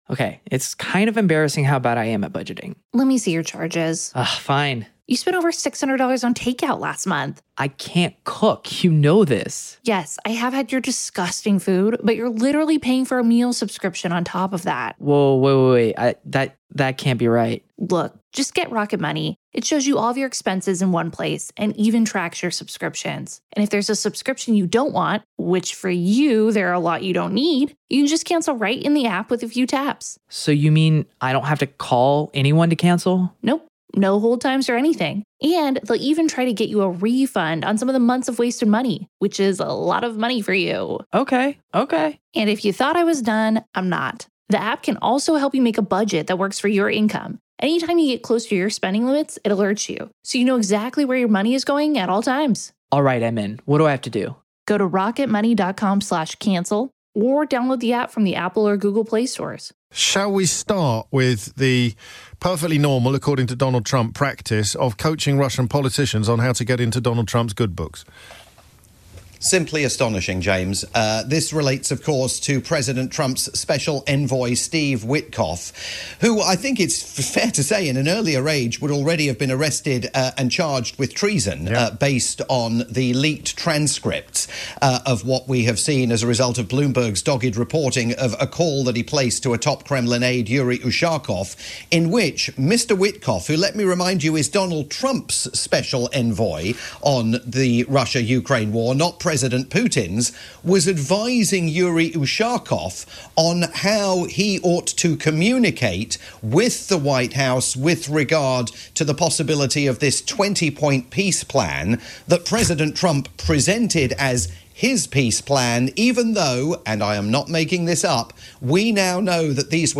live roundup with James O'Brien on the UK's LBC.